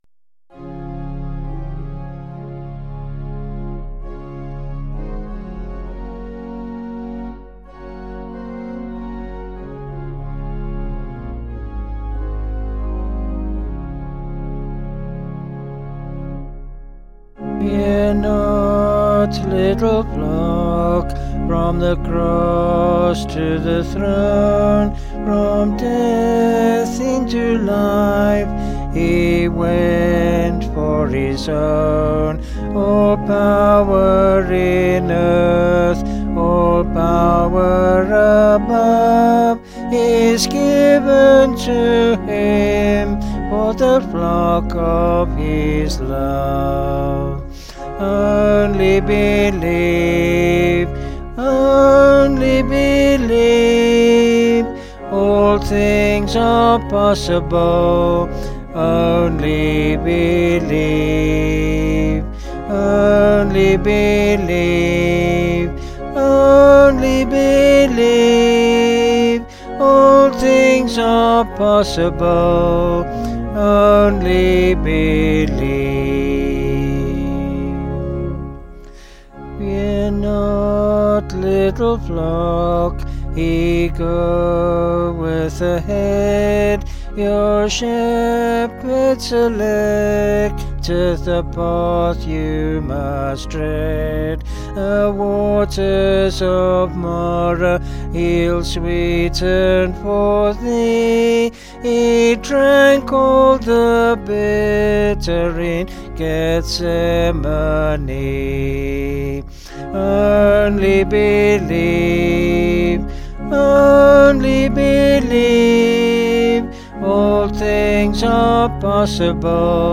Vocals and Organ   264.8kb Sung Lyrics